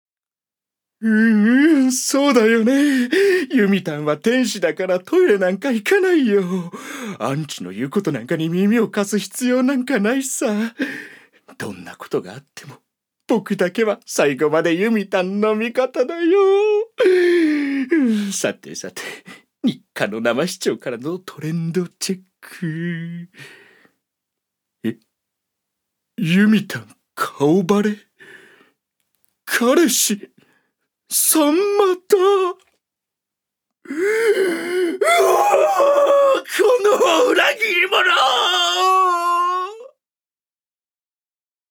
所属：男性タレント
セリフ６